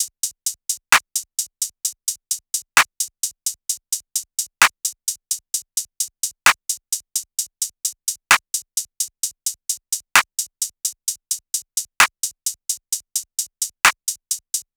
SOUTHSIDE_beat_loop_grey_top_01_130.wav